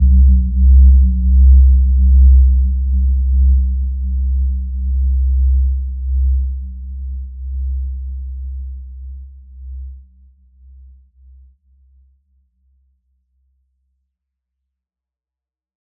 Gentle-Metallic-3-C2-p.wav